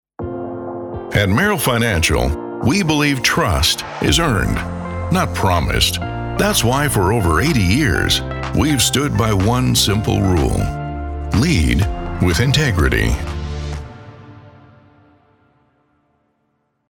Commercieel, Opvallend, Veelzijdig, Warm, Zakelijk
Corporate
He records from a professionally appointed home studio for clients across the globe.
His voice can be described as Warm, Deep, Authoritative, Calming, Confident, Authentic, Rustic, and Masculine.